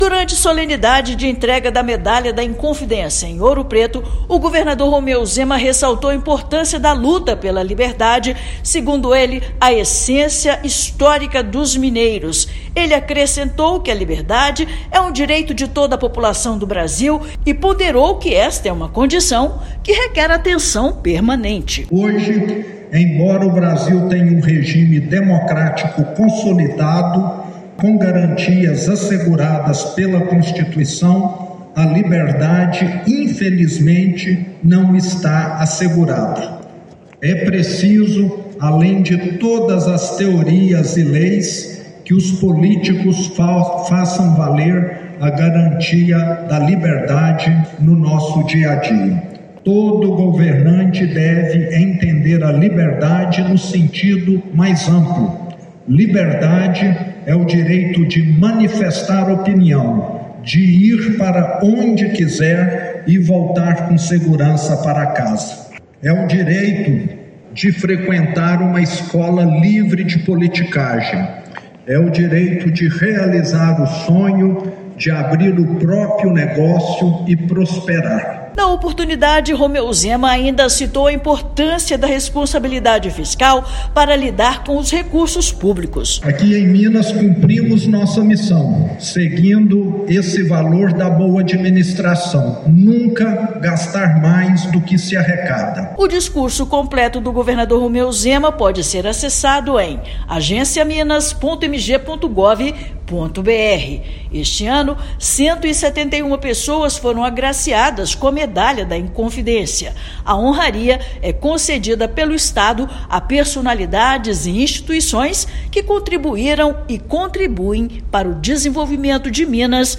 Solenidade realizada em Ouro Preto homenageou 171 pessoas entre personalidades e instituições. Ouça matéria de rádio.
MEDALHA_INCONFIDÊNCIA_-_DISCURSO_DO_GOVERNADOR.mp3